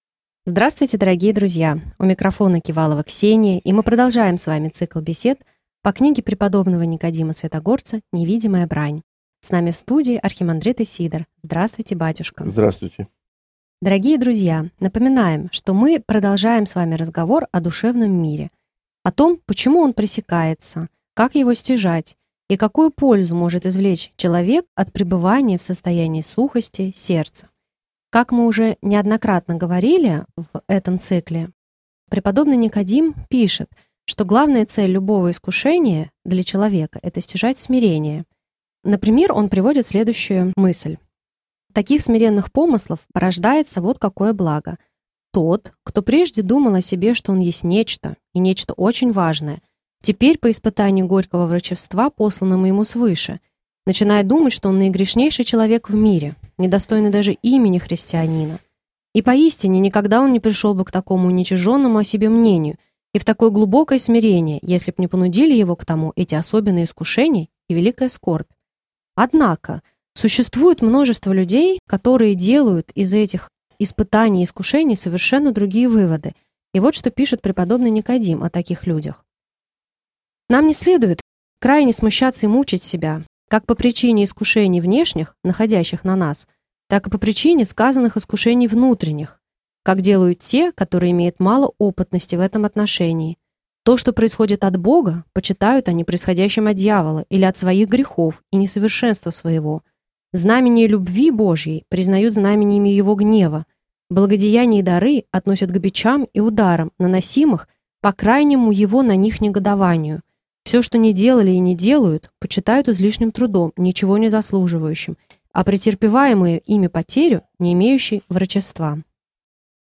Беседы по книге преподобного Никодима Святогорца